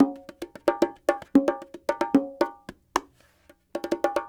44 Bongo 12.wav